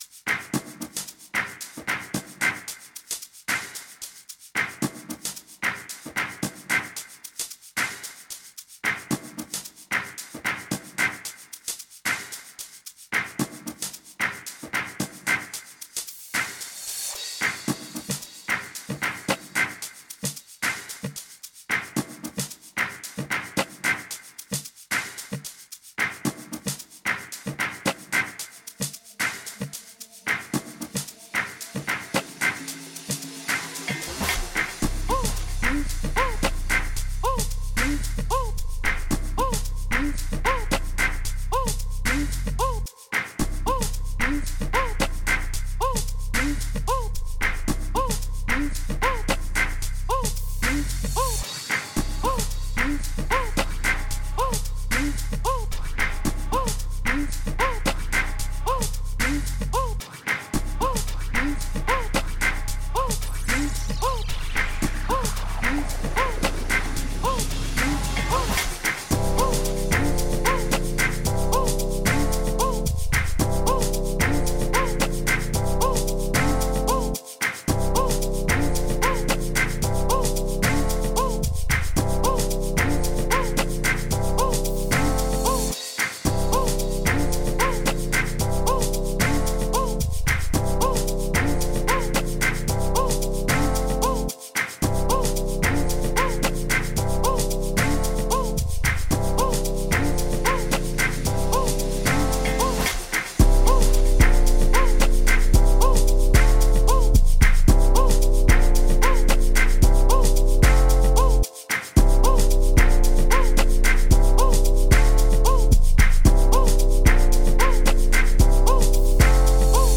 06:58 Genre : Amapiano Size